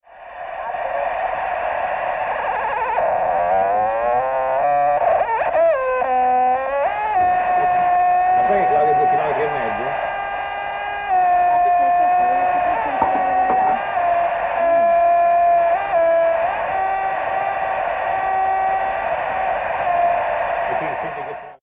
Le registrazioni originali su cassette sono tuttora conservate nel mio archivio, e le digitalizzazioni degli anni 1993-1995 sono state effettuate con software buoni sebbene non eccelsi, e a risoluzioni "decenti" a 44Khz.
rec lancio1 K2 mono filtrato.mp3